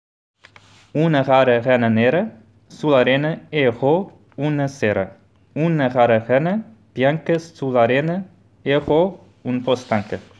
But today I propose to you a few Italian tongue-twisters read by some foreigners who live in Oslo, because I would like you to listen to their different accents and underline the beauty of each.
reads the words “rana“, “rara” and “erró” with the French r and his pronunciation is pretty nasal